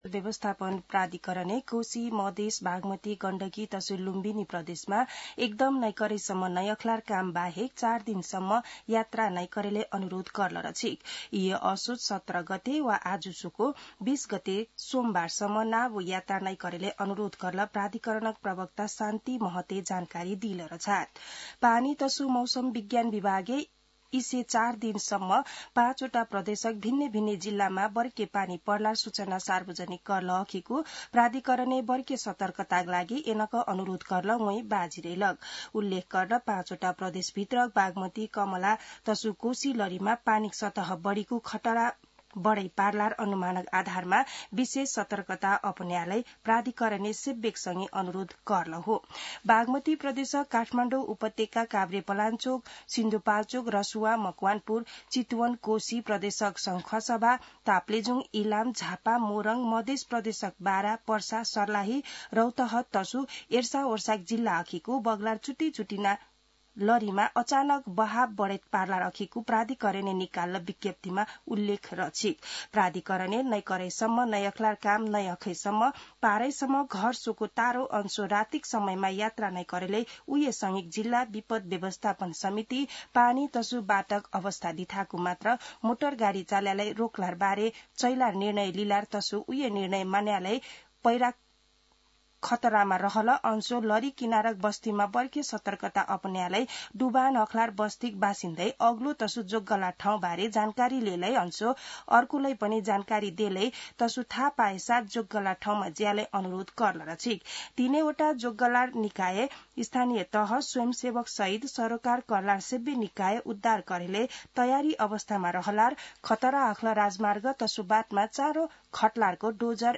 दनुवार भाषामा समाचार : १७ असोज , २०८२
Danuwar-News.mp3